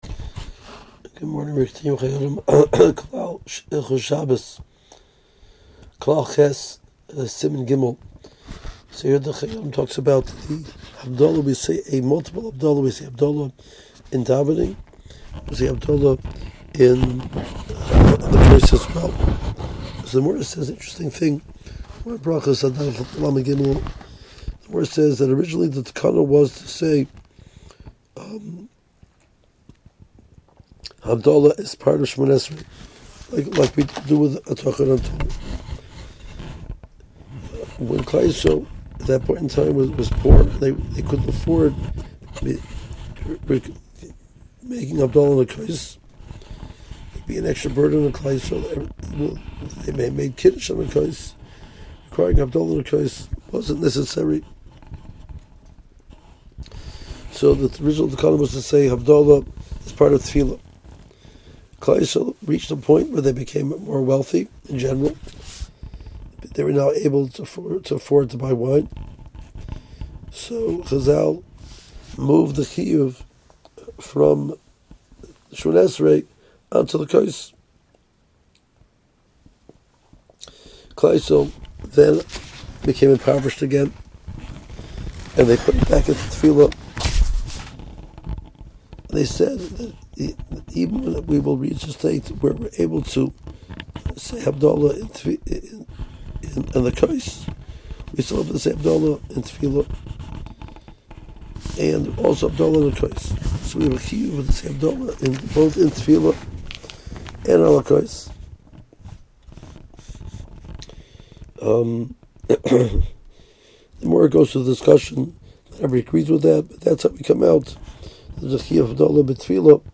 • 5 Min. Audio Shiur Including Contemporary Poskim